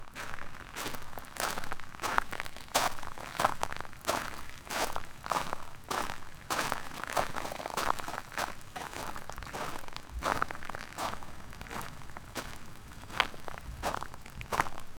footsteps-in-wet-dirt-aft-ommr6sf6.wav